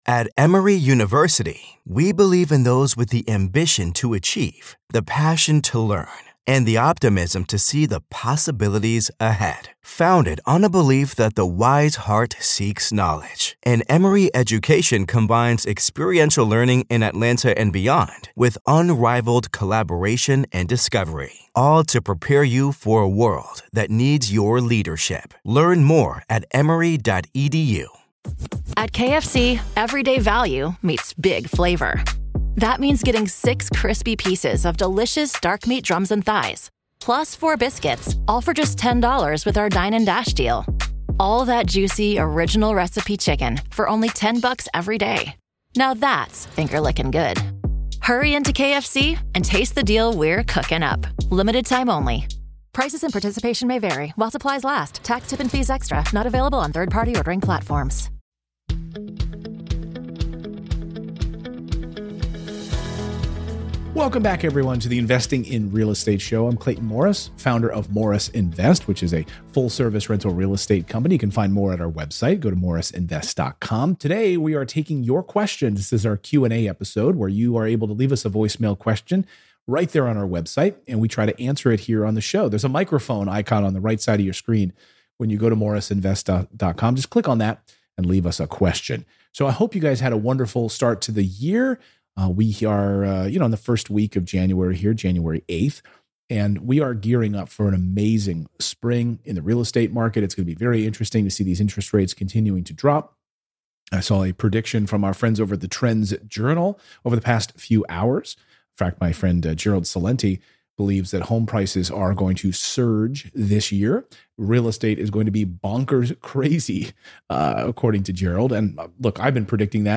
Today’s first caller used a Veterans Affairs loan to house hack.